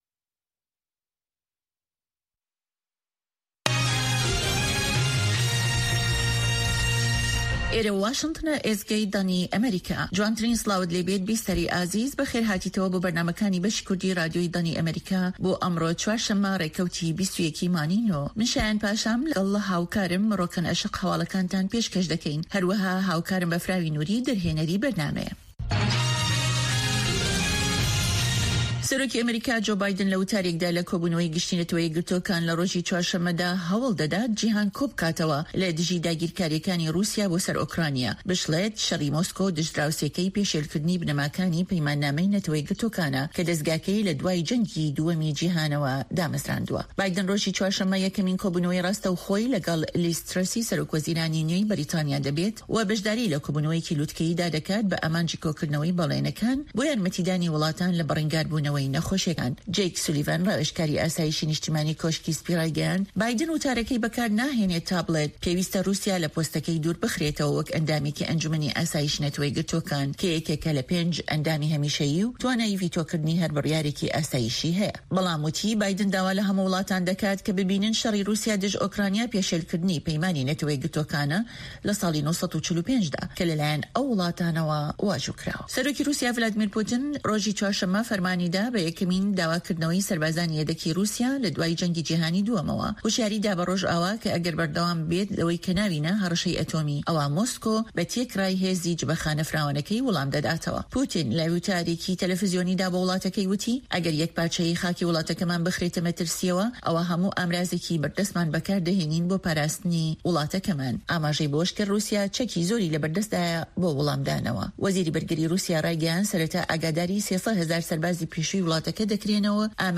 هەواڵە جیهانییەکان 1